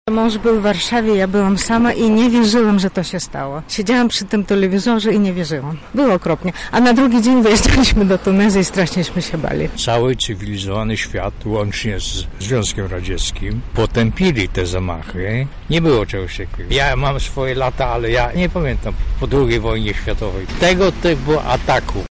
Zapytaliśmy mieszkańców Lublina o wspomnienia związane z tamtym dniem: